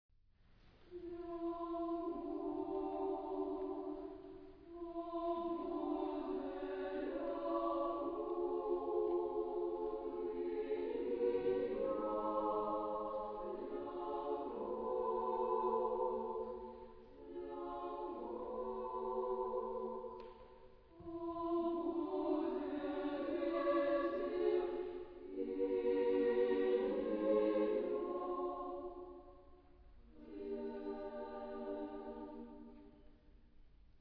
Genre-Style-Forme : Profane ; Chanson ; contemporain
Type de choeur : SSAA  (4 voix égales )
Tonalité : diverses